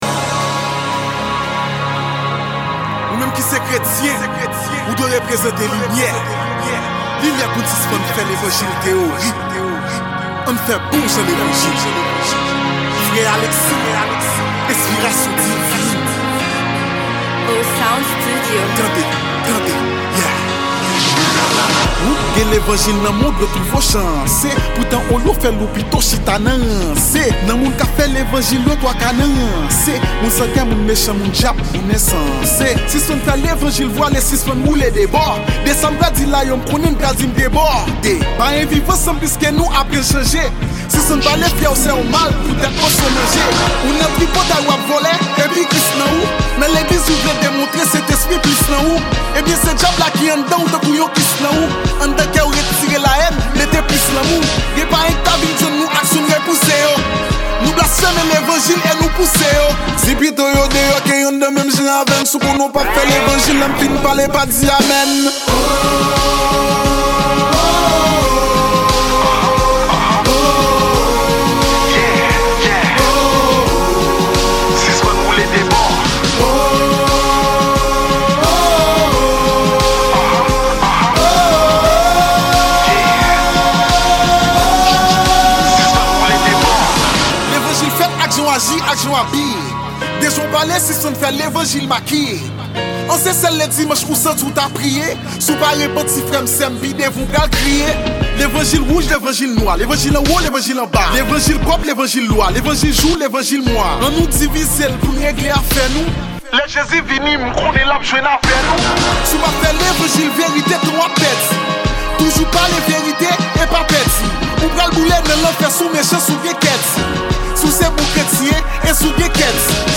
Genre: Rap Gospel.